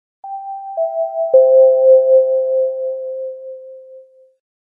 • Качество: высокое
Короткий звук оповещения о новом сообщении на телефоне